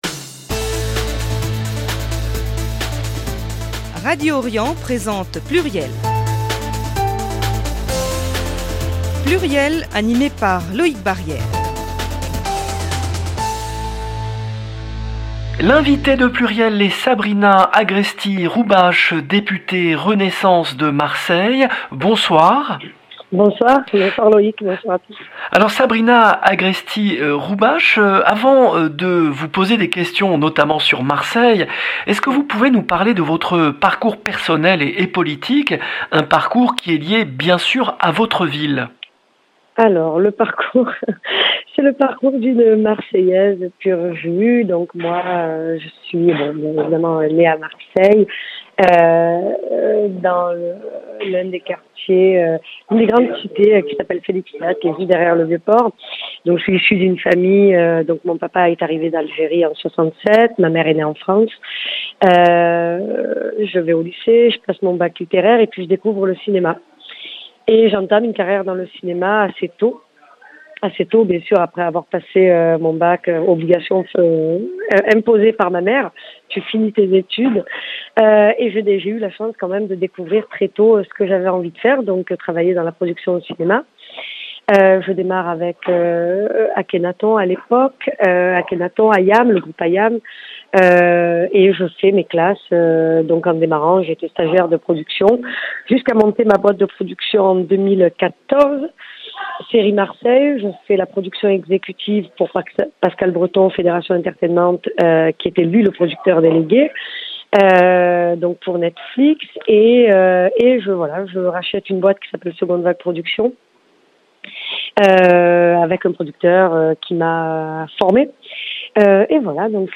L’invitée de PLURIEL le vendredi 30 septembre 2022 était Sabrina Agresti-Roubache, députée de Marseille, porte-parole du groupe Renaissance à l’Assemblée Nationale